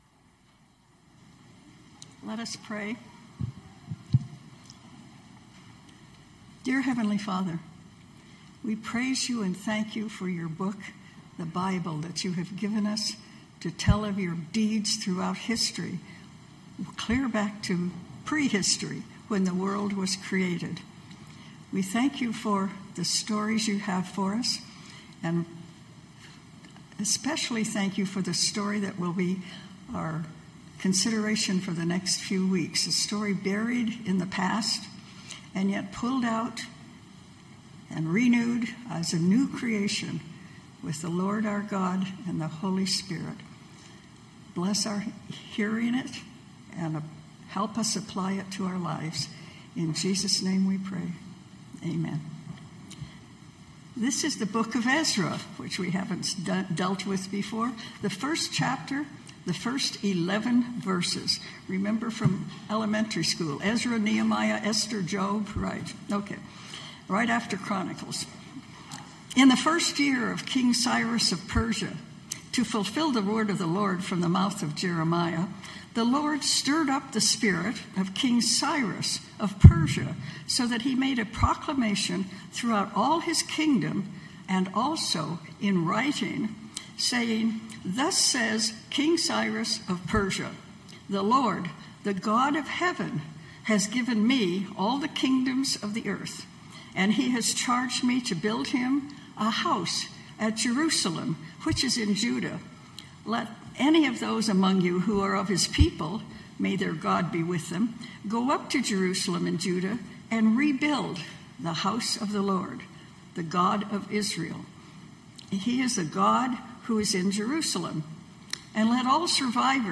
Knox Pasadena Sermons Permit Issued on a Home Rebuild Aug 17 2025 | 00:24:06 1x 00:00 / 00:24:06 Subscribe Share Spotify RSS Feed Share Link Embed